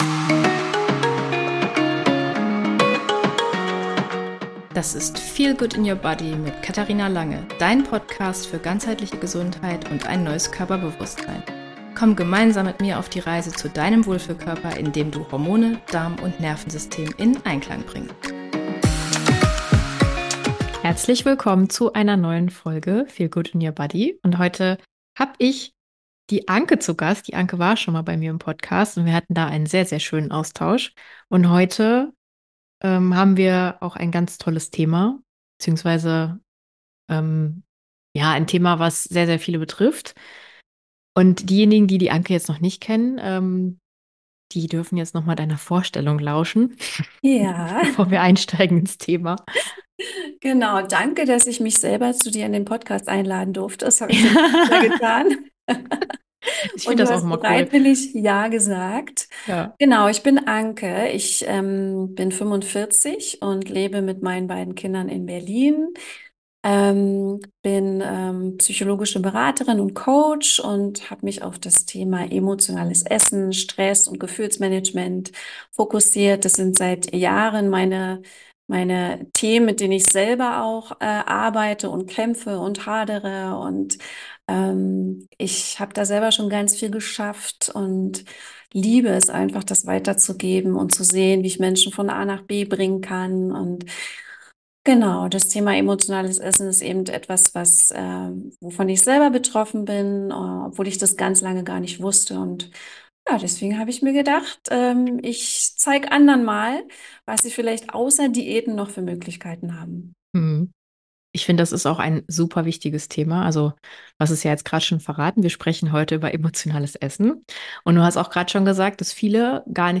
Es wird ehrlich, emotional und inspirierend – wie ein Gespräch mit der besten Freundin, das dir neue Perspektiven gibt und dich daran erinnert, dass du mehr bist als dein Essverhalten.